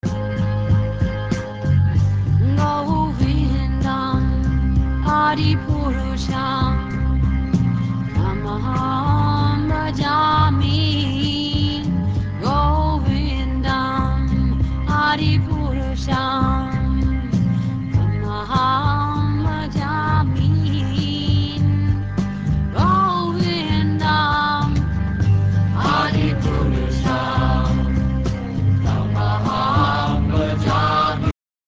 mridanga (tambour indien)